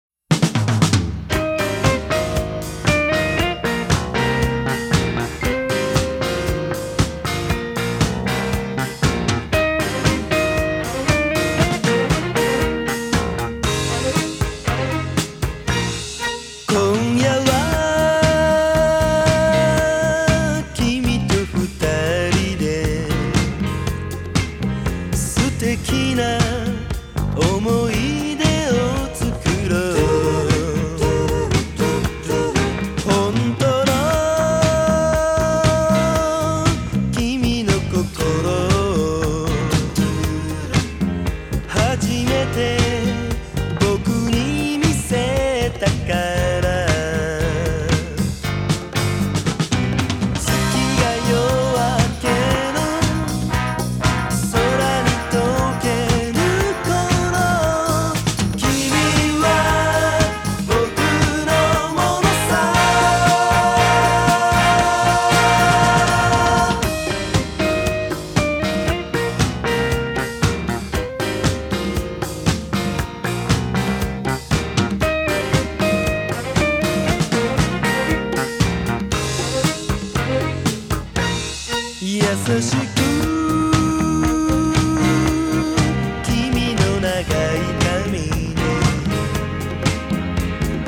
極上の和製フィリーソウル/クロスオーバーソウル
CITY POP / AOR
華麗なフィリーソウル調の弦のアレンジと骨太のリズム隊
● オリジナルのマスター音源を使用したリマスター音源。